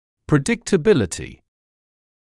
[prɪˌdɪktəˈbɪləti][приˌдиктэˈбилэти]предсказуемость